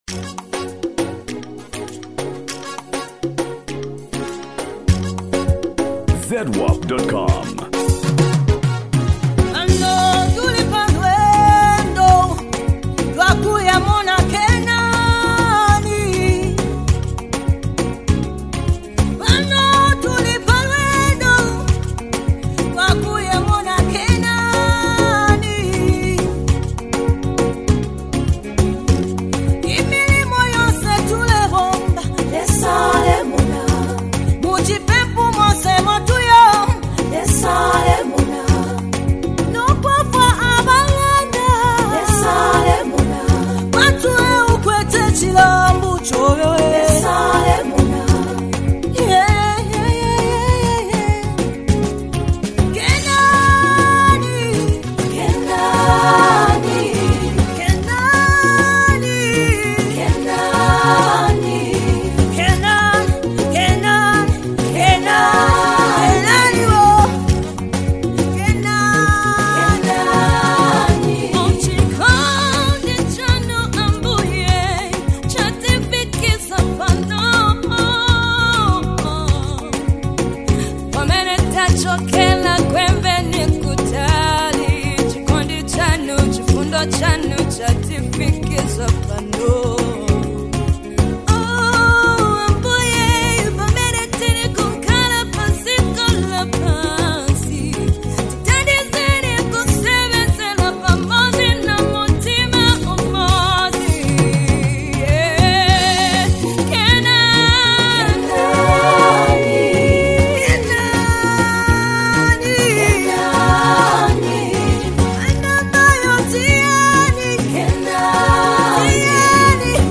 Latest Praise and Worship Gospel Music 2022.
Sensational Zambian female gospel musician